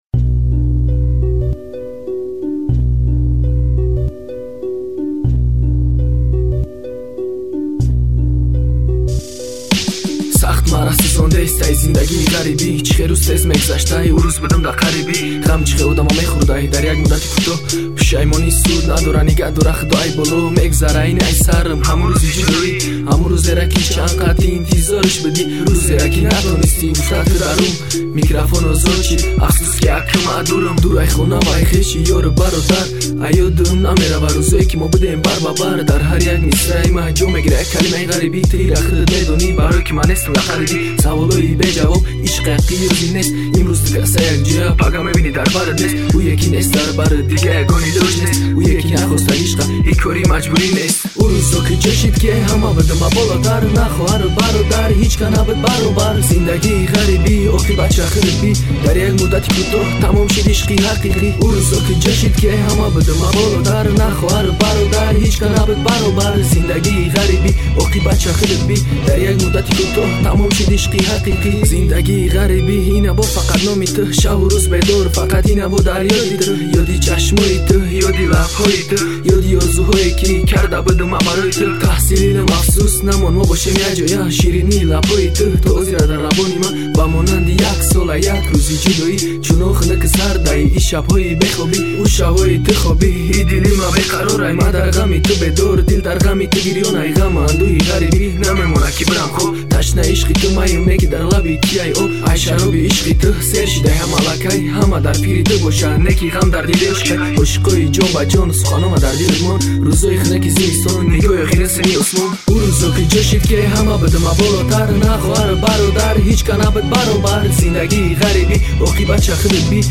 Главная » Файлы » Каталог Таджикских МР3 » Тадж. Rap